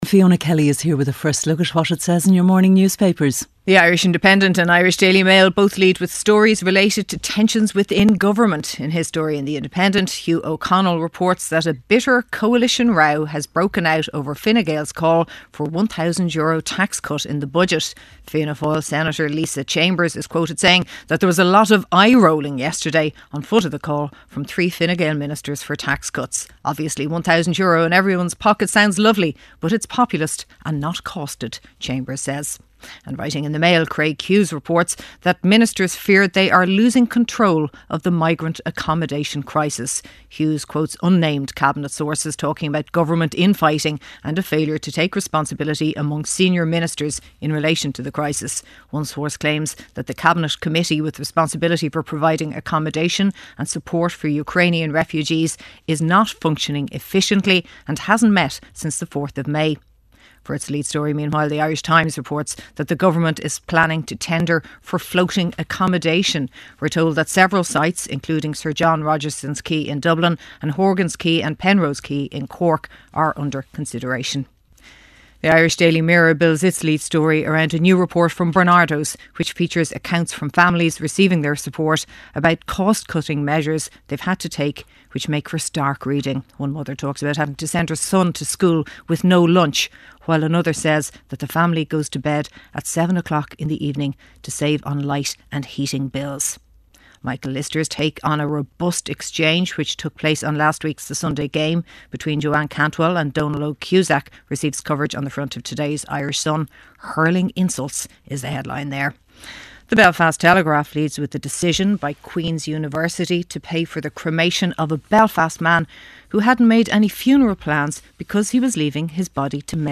Weather Forecast - 24.05.2023